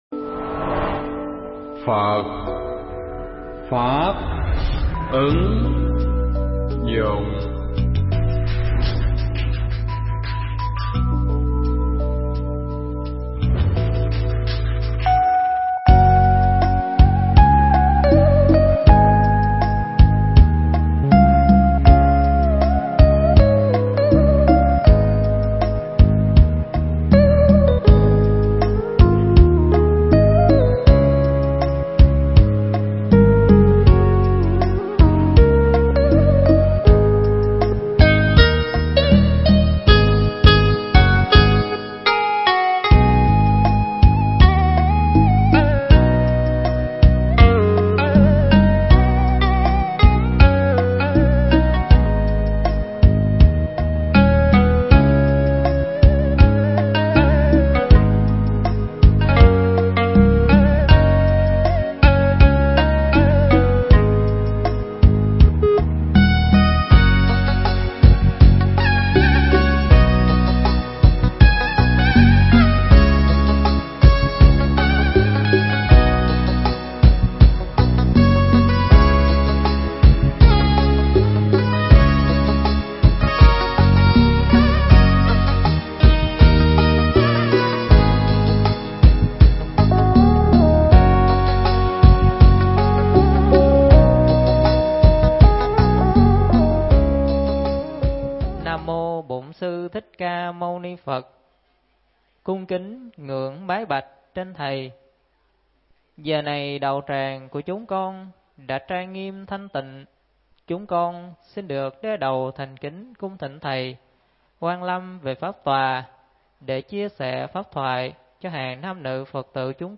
giảng tại tu viện Tường Vân ngày 24 tháng 12 năm 2015